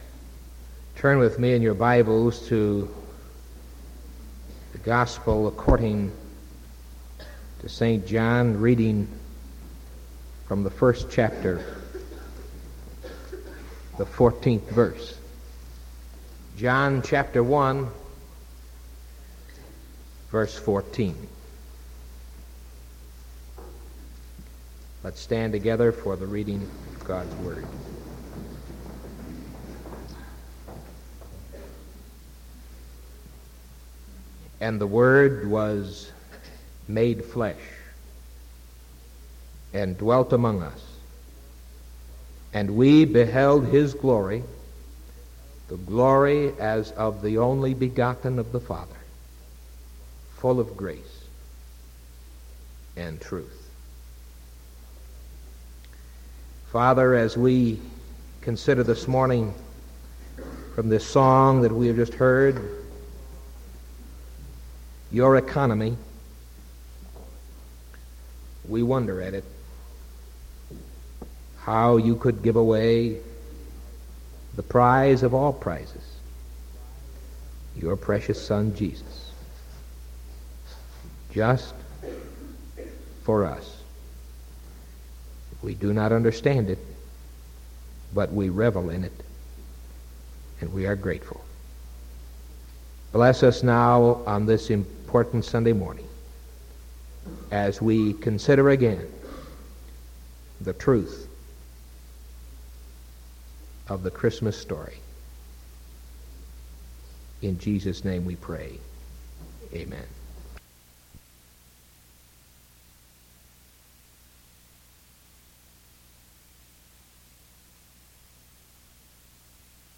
Sermon December 22nd 1974 AM